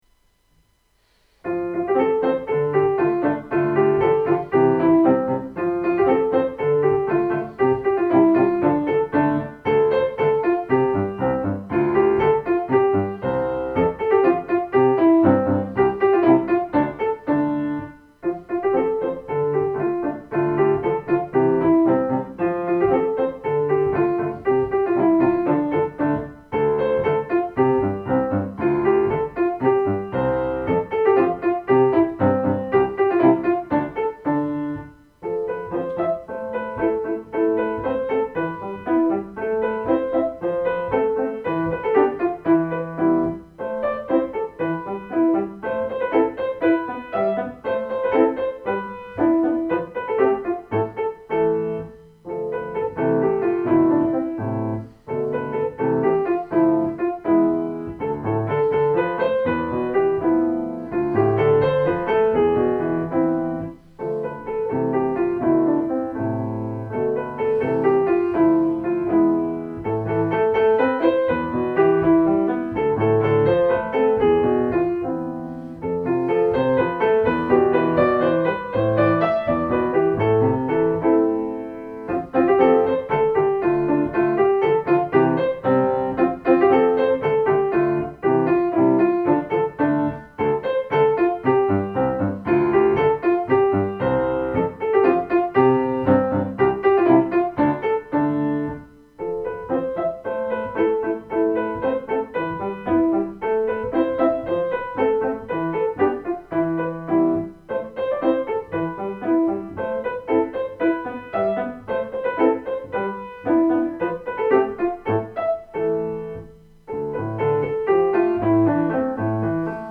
Deze ervaringen hebben me geïnspireerd om dit meerdelige werk te schrijven, het zijn sfeerbeelden in meditterane sfeer.